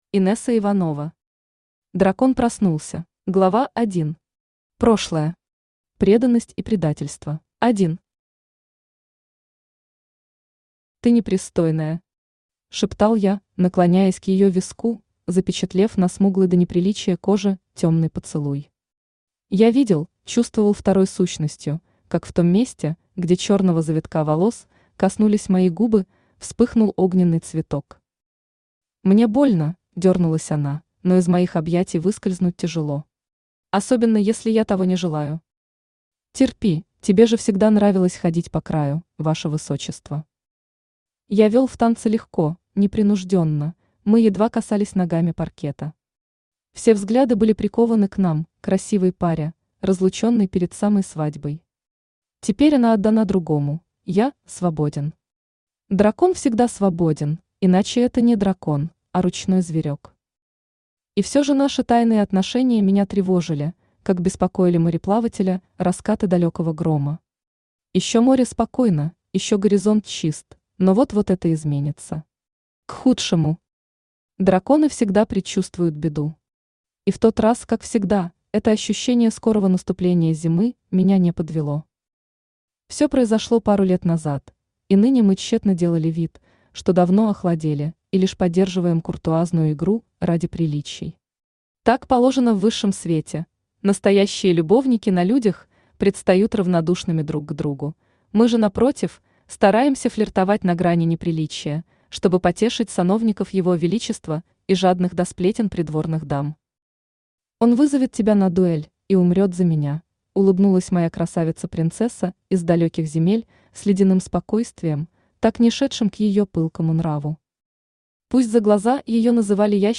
Аудиокнига Дракон проснулся | Библиотека аудиокниг
Aудиокнига Дракон проснулся Автор Инесса Иванова Читает аудиокнигу Авточтец ЛитРес.